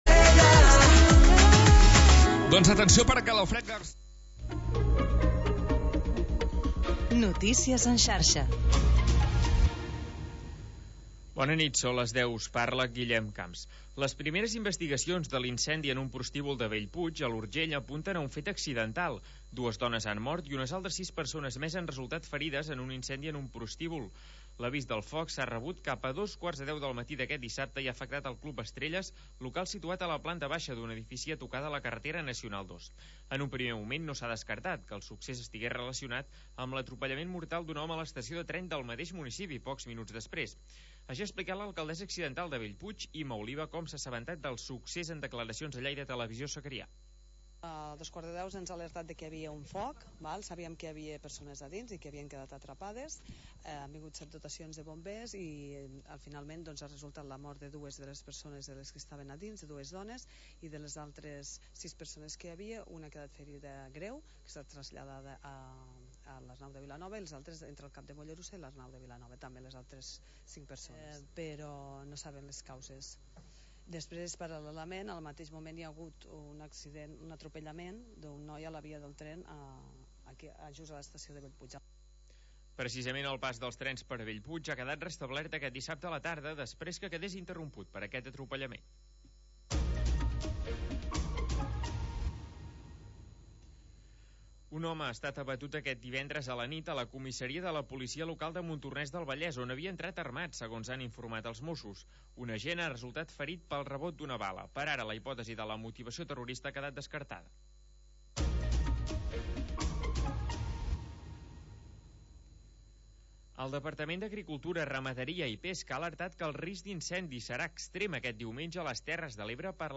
Selecció musical de Dj.